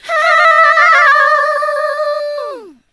SCREAM10  -L.wav